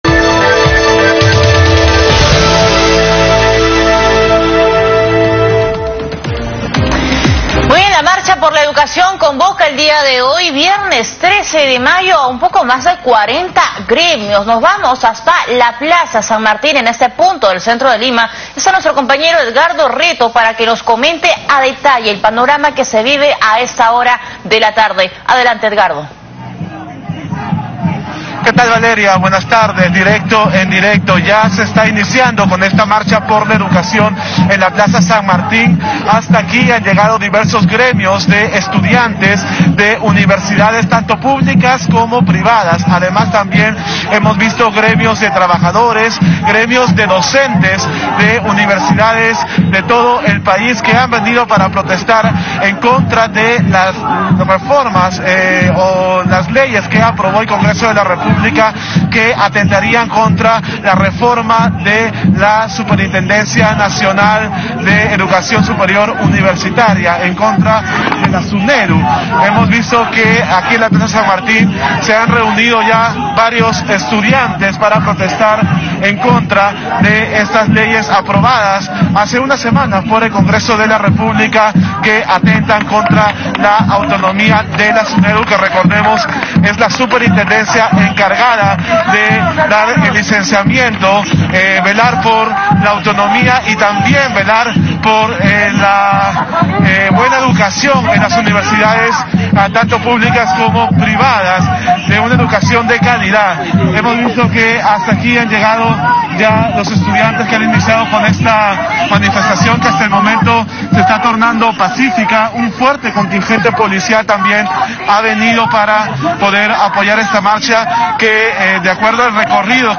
Enlace en vivo. Desde el Cercado de Lima, en la Plaza San Martín, se informó que se realizó la Marcha por la Educación, donde al menos 40 Gremios se movilizaron por la contrarreforma a Sunedu realizado por el Congreso de la República. Se detalló que la PNP resguarda a los protestantes que quieren llegar al Parlamento.